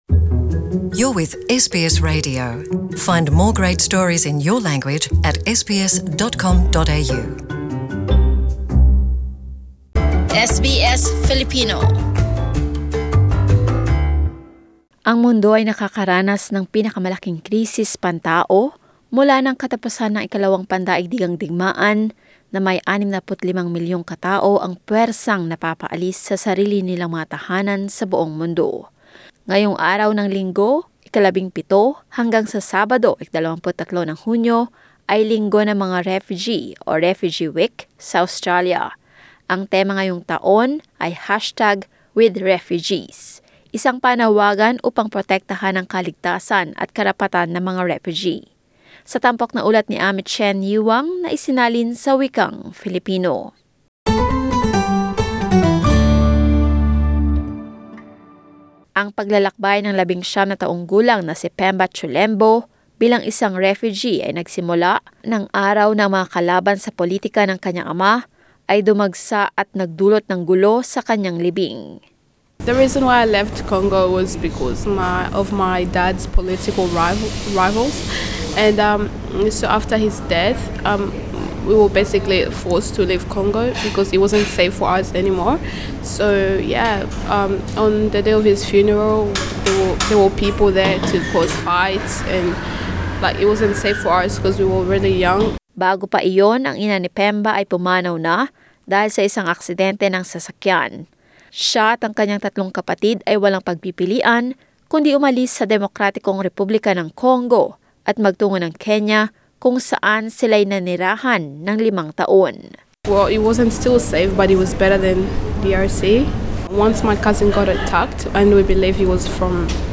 Hunyo 17 (Linggo) hanggang Hulyo 23 (Sabado) ang Refugee Week sa Australya. Itong linggo, kakausapin ng Settlement Guide ang mga refugee na naninirahan sa Australya.